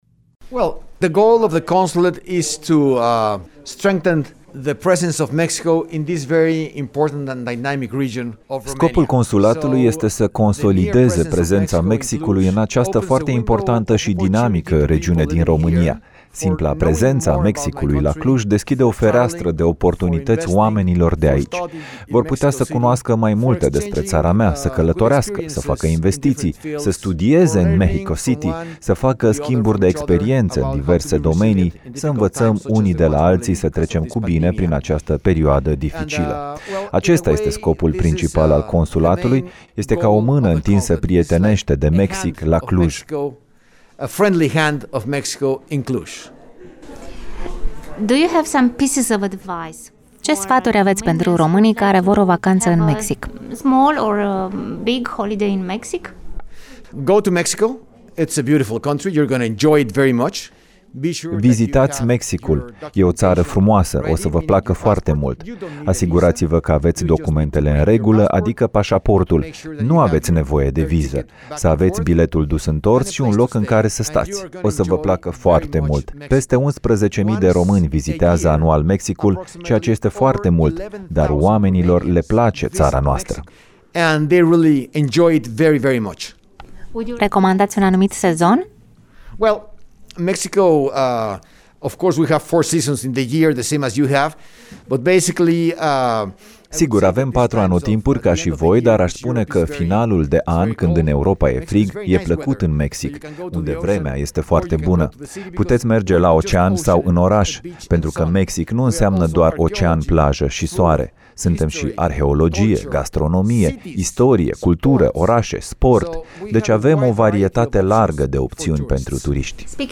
Ambasadorul Mexicului la București, domnul José Luis Ordorica, este genul de diplomat care își face prieteni în timpul plimbărilor cu bicicleta.